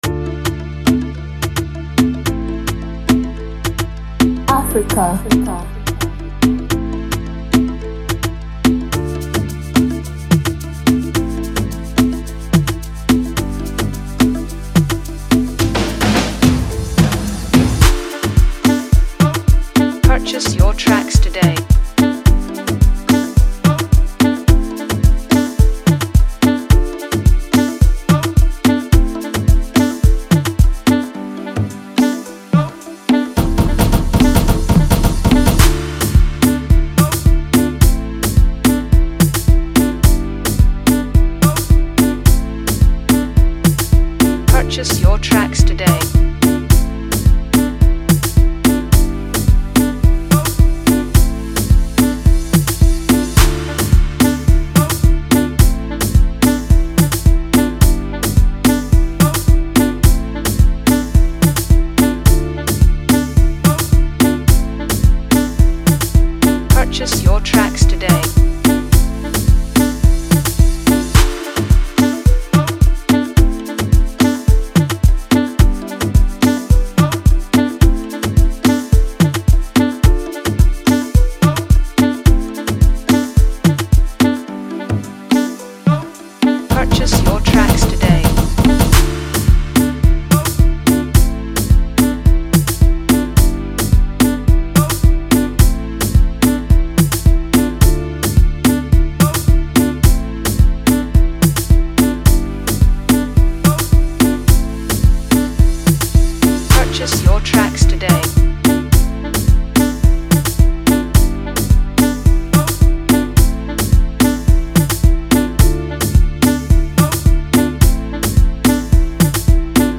With its infectious melody and irresistible vibe